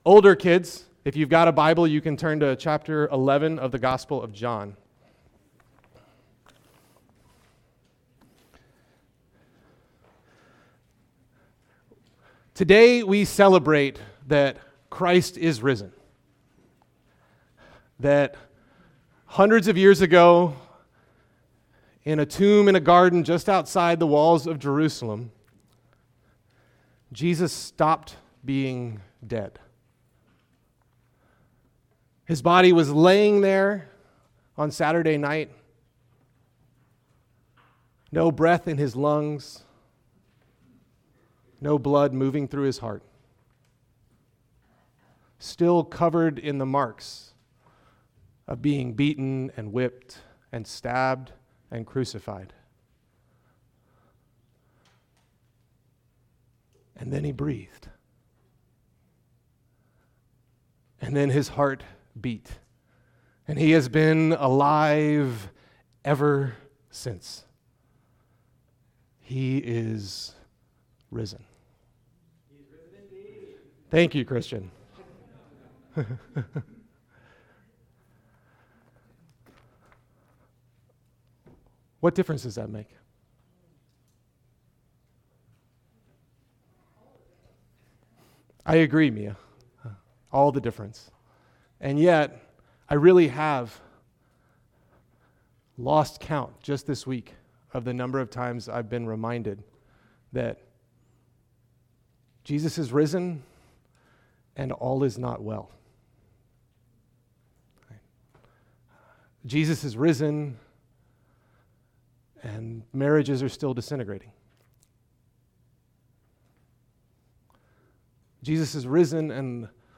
Easter Sunday
Teachings